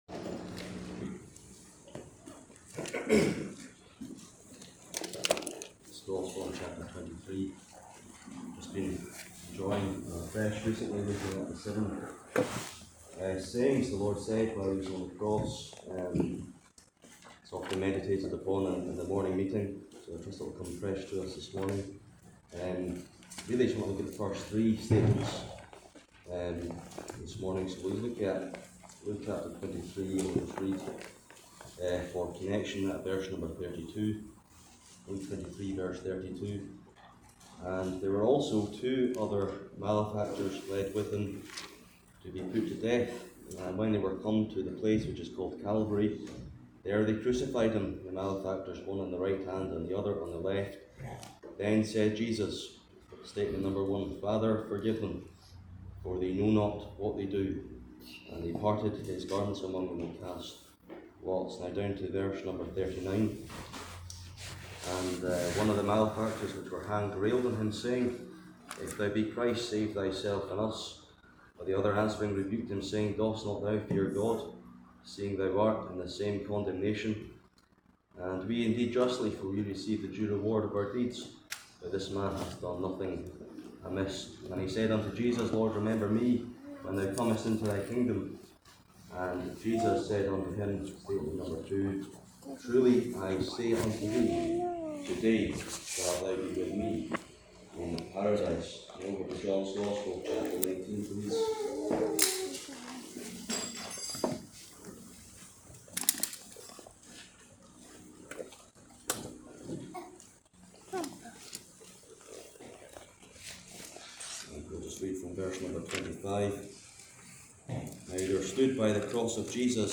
A rich Christ exalting exhortation with a practical touch for the Christian navigating the existential struggles of life.